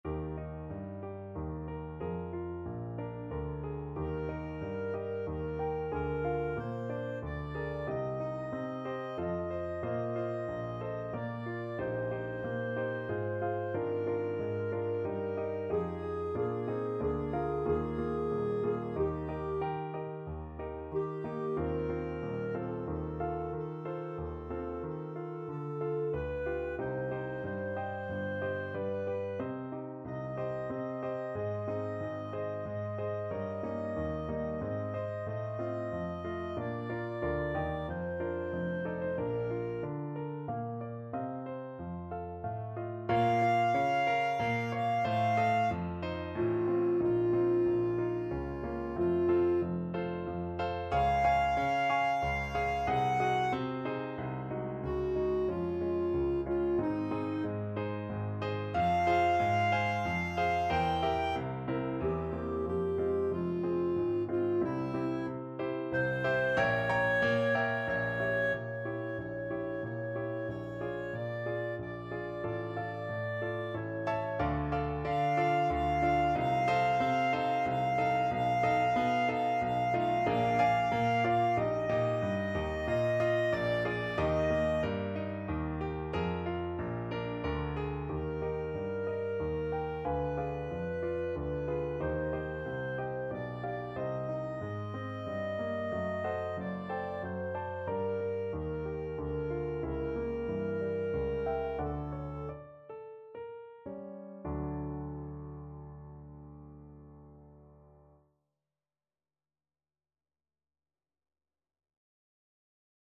Clarinet version
3/4 (View more 3/4 Music)
~ = 92 Larghetto
Classical (View more Classical Clarinet Music)